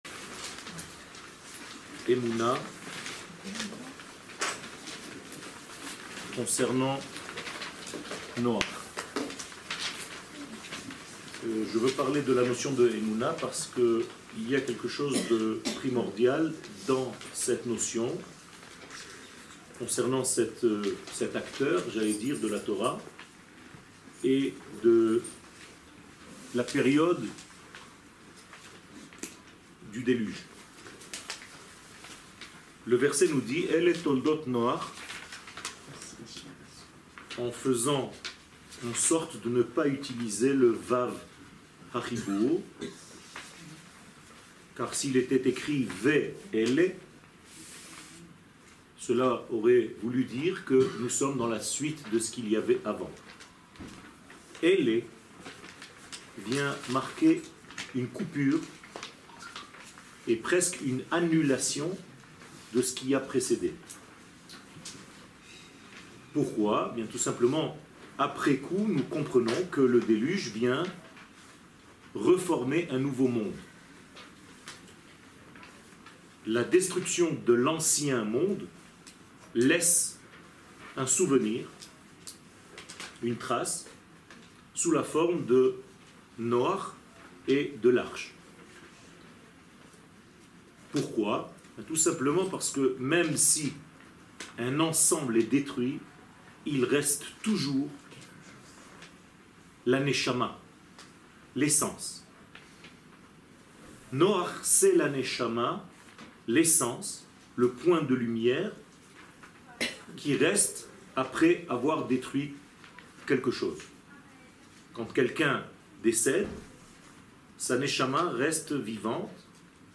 שיעורים, הרצאות, וידאו